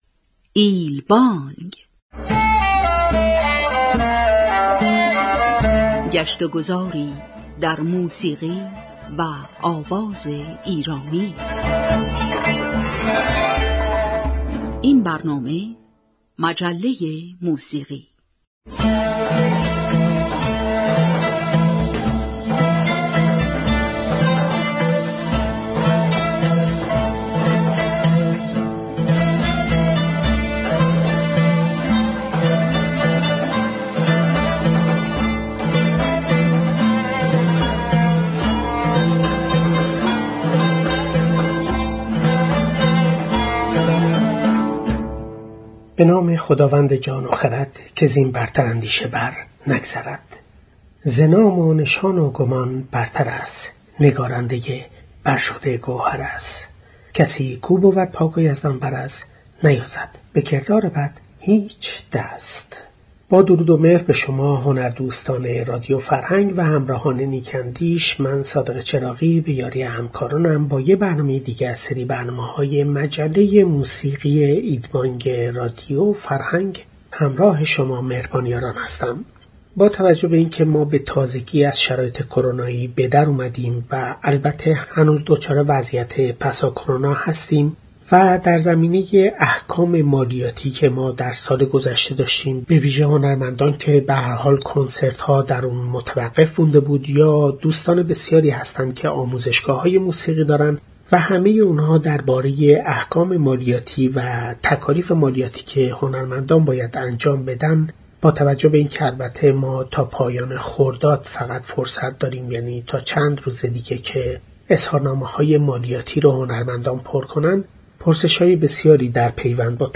یک وکیل مالیاتی در برنامه این هفته «ایل بانگ» اظهار کرد: هنرمندان برای استفاده از معافیت مالیاتی حتماً تا قبل از پایان مهلت اظهارنامه مالیاتی خود را با مشورت مشاوران مالیاتی تنظیم و سپس آن را ارسال کنند.